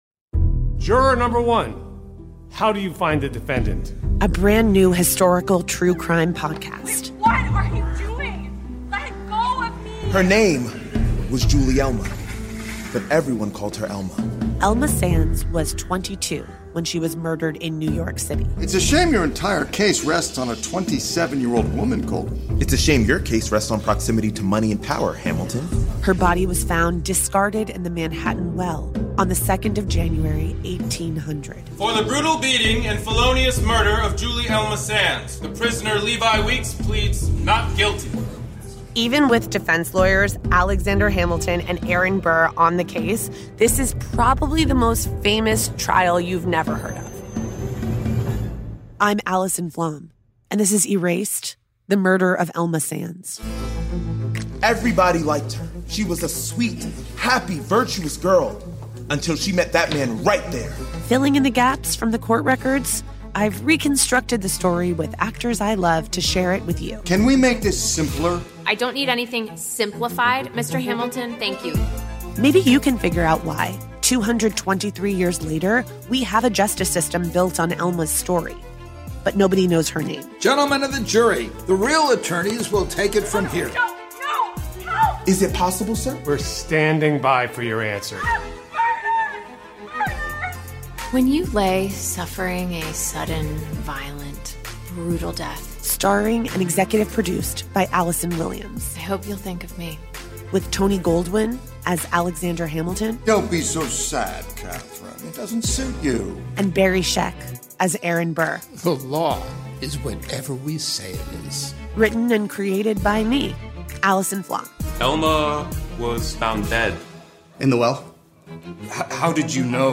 Through flashbacks & testimony recreated in modernized language and narrated by